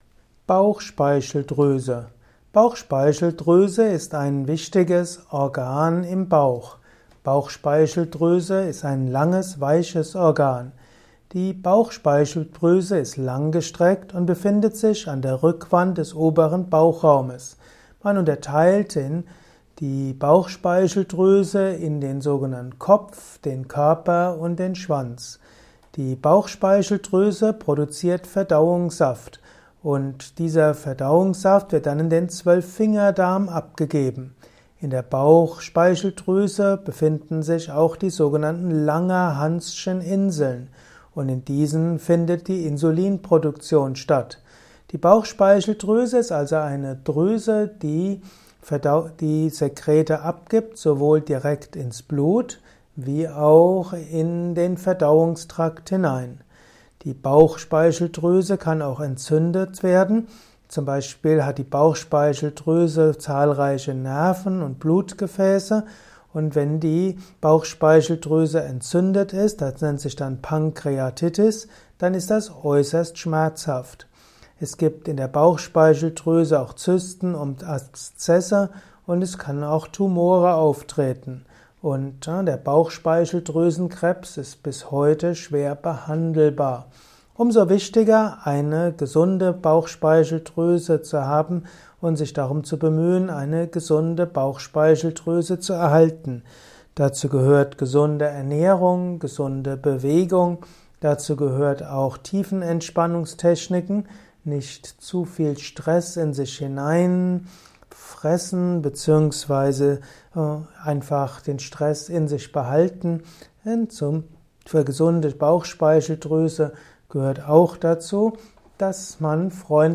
Audiovortrag zum Thema Bauchspeicheldruese
Er ist ursprünglich aufgenommen als Diktat für einen